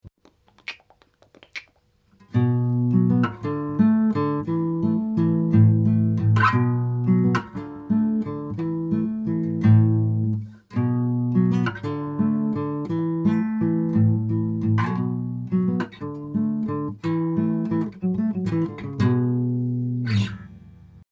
Но все равно, слышите, нет густого, глубокого звука.
:ps:Купил Light, но от старых Medium практически не отличить.